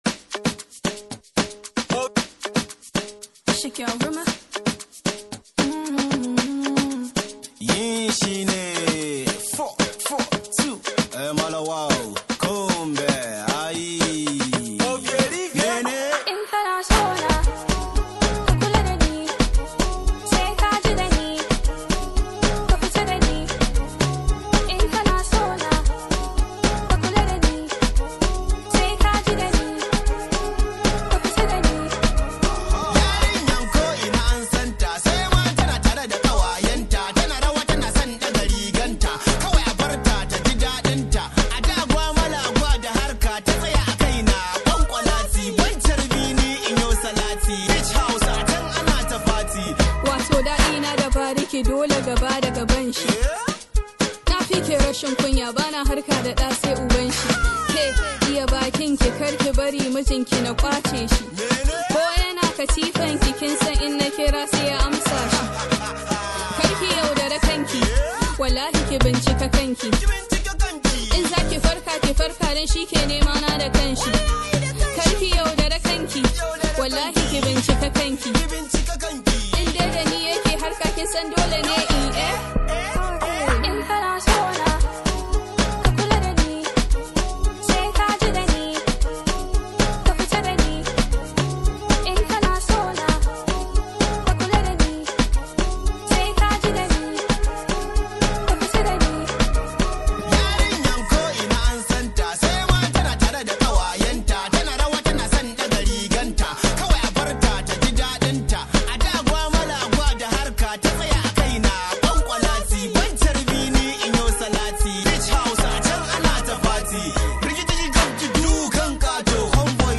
Hausa Hip Hop